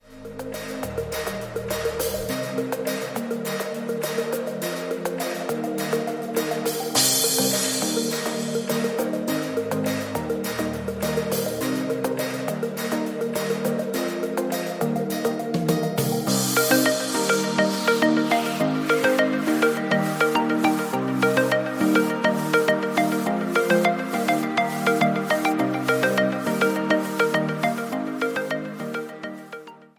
Eb
MPEG 1 Layer 3 (Stereo)
Backing track Karaoke
Pop, 2010s